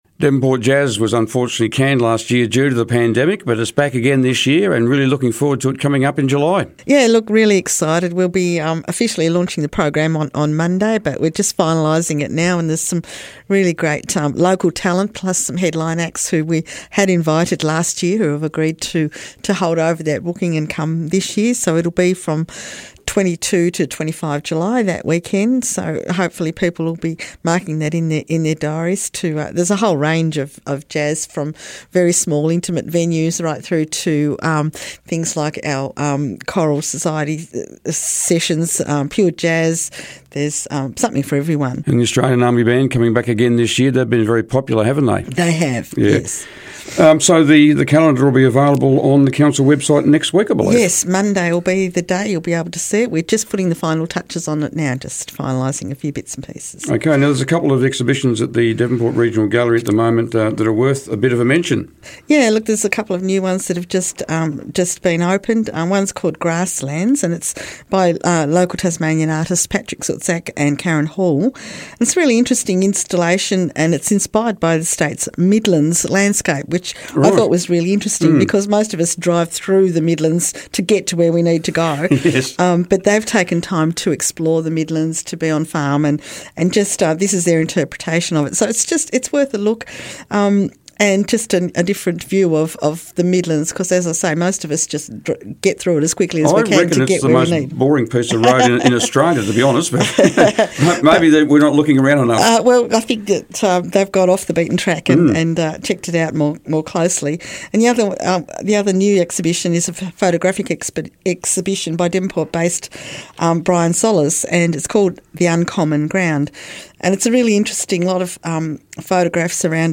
Devonport Mayor Annette Rockliff was today's Mayor on the Air.